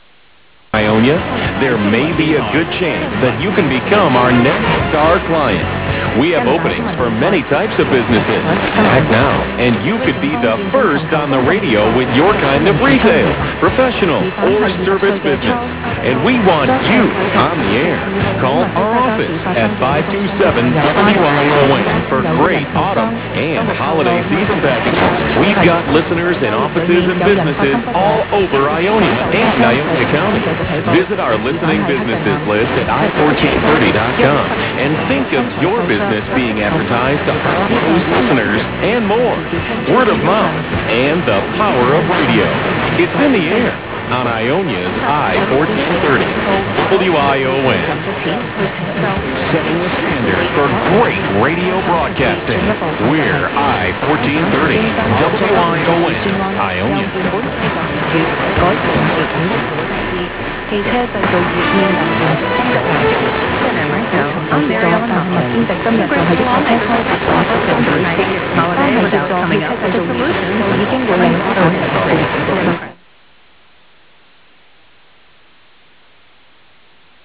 This page contains DX Clips from the 2008 DX season!